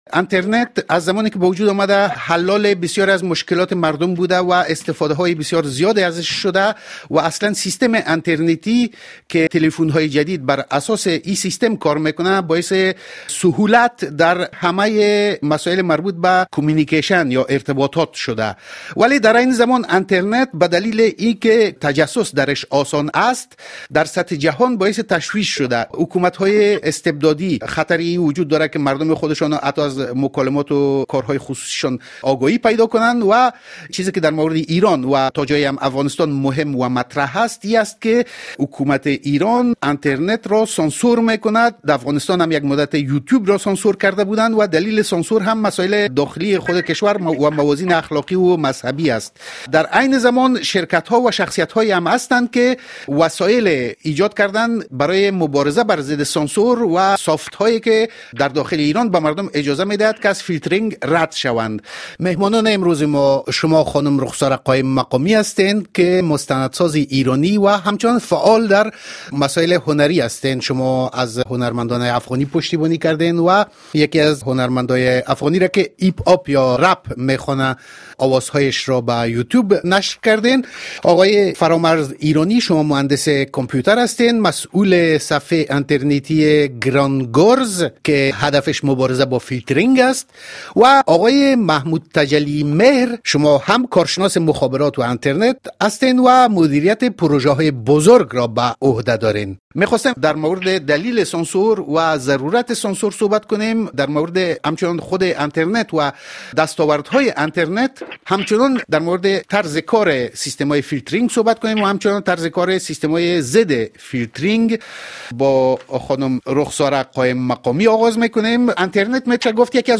مصاحبه با رادیو فرانسه در مورد سانسور اینترنت در ایران و امنیت فیلترشکن‌ها